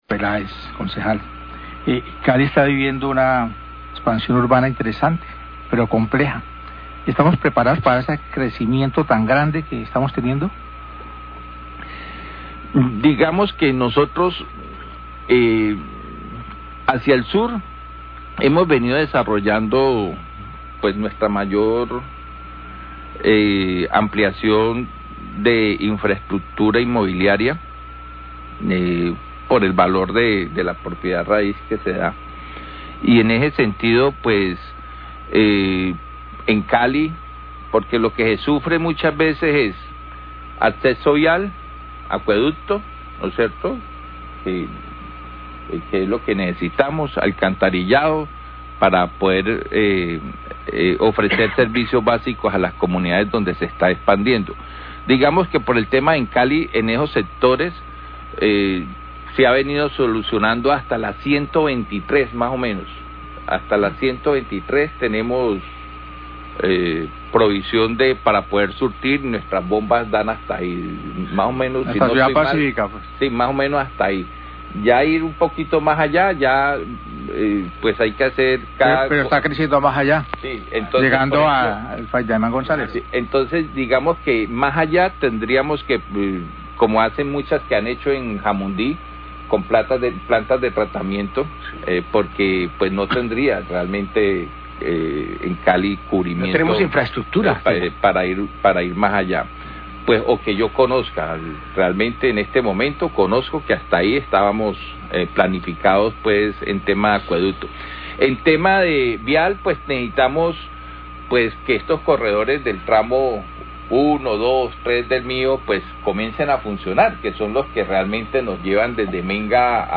Concejal Peláez sobre zona de expansión y plaza de Siloé, Noticiero Relámpago 745am
Radio